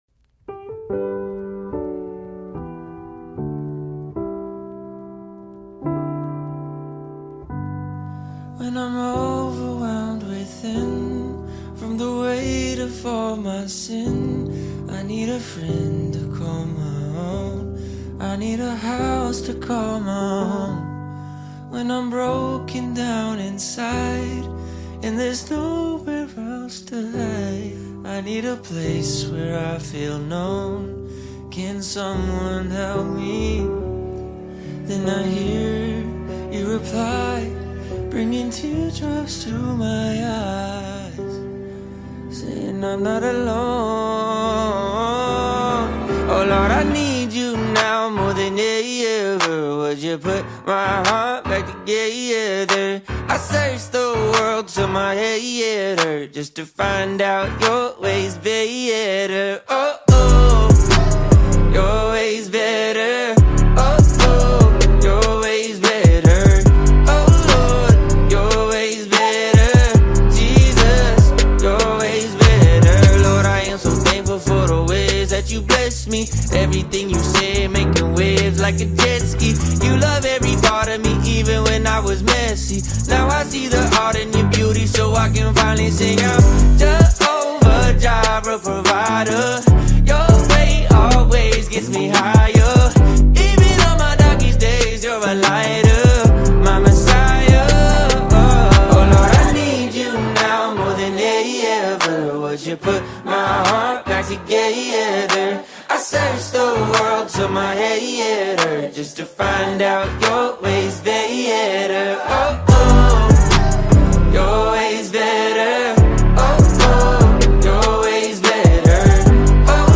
heartfelt worship with contemporary pop and hip-hop vibes
With smooth vocals and catchy melodies
Gospel Songs